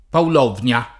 paulonia [ paul 0 n L a ]